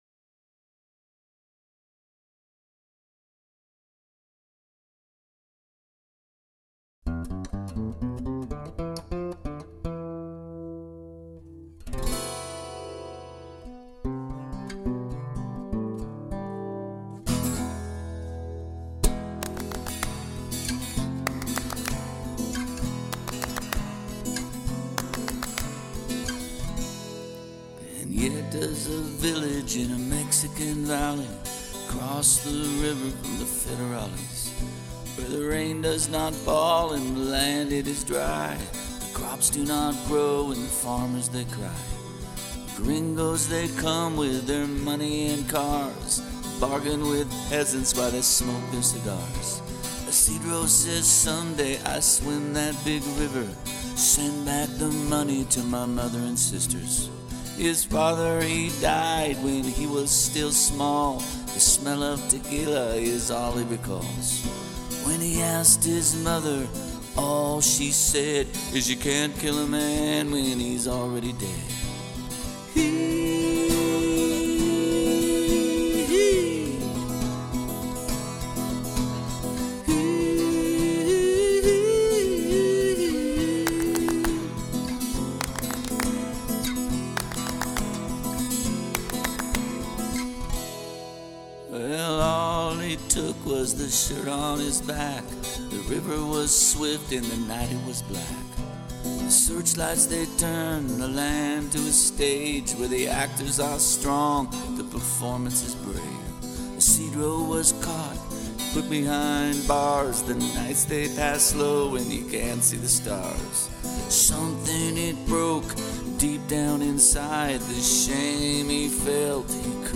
Folk song